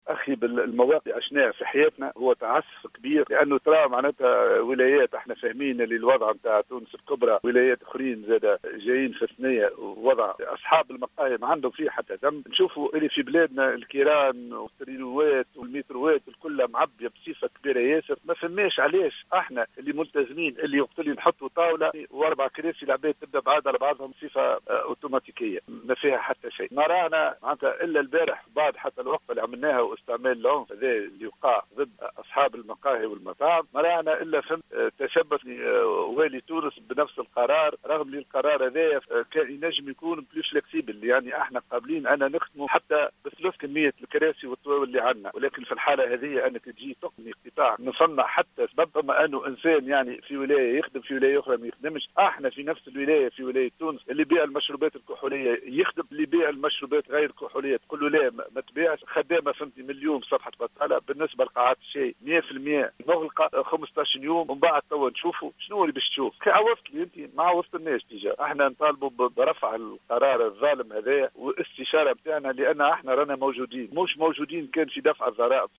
في اتصال هاتفي للجوهرة أف- أم